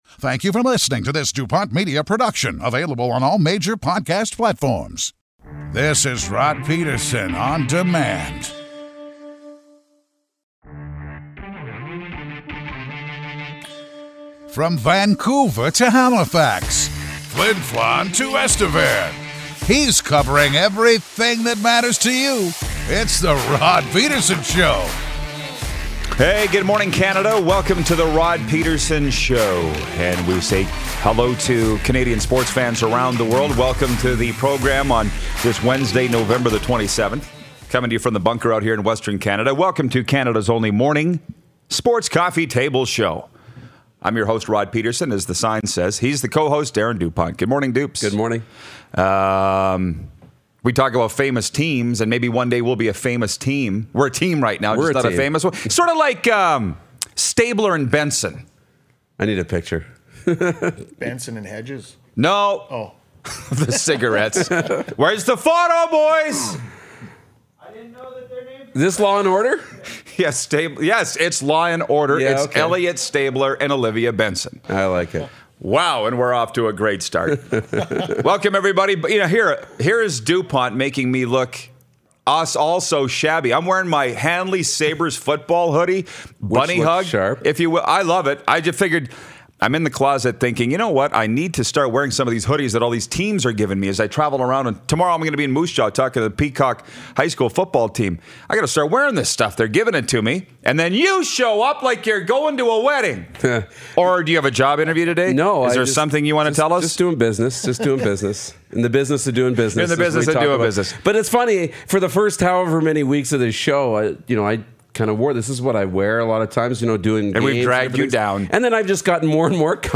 Micheal Landsberg, Host of TSN’s FirstUp, calls in!